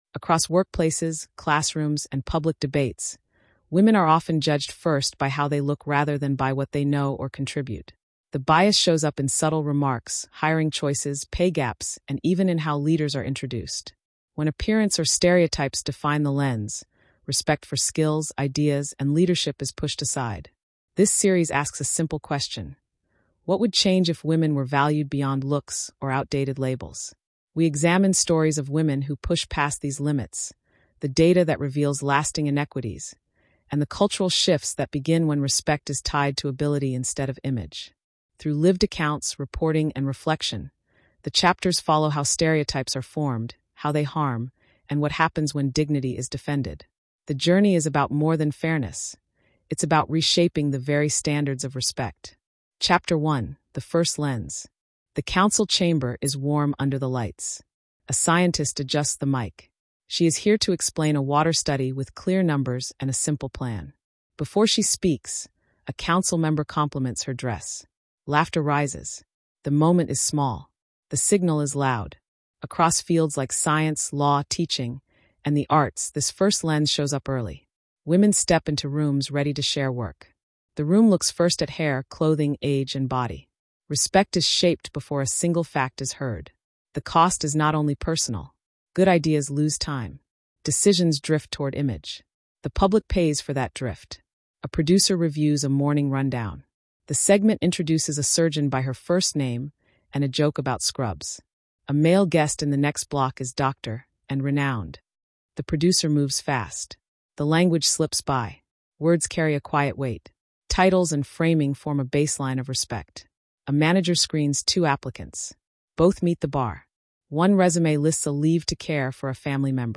Told in a clear, journalistic voice, the series blends lived accounts, reporting turns, and data insights to reveal the hidden costs of bias—and the tangible gains when respect is tied to skill.